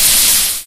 default_cool_lava.2.ogg